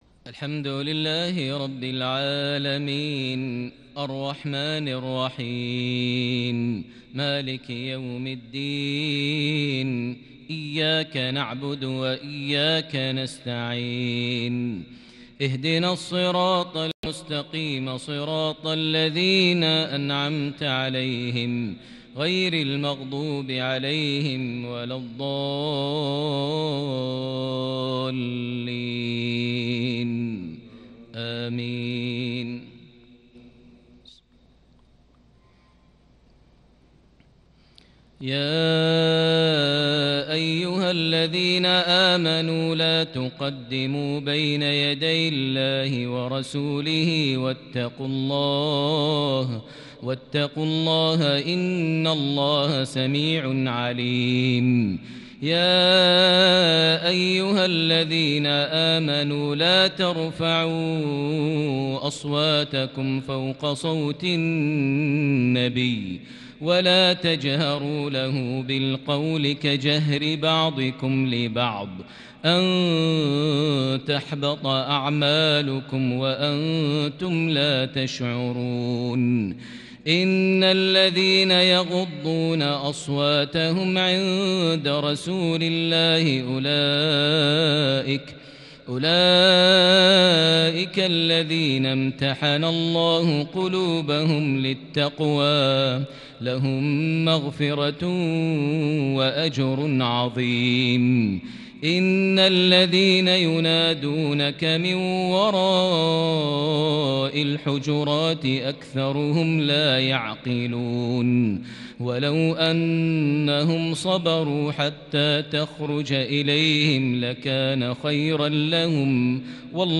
تلاوة متفردة من سورة الحجرات (1-13) عشاء الجمعة 3 ذو الحجة 1441هـ > 1441 هـ > الفروض - تلاوات ماهر المعيقلي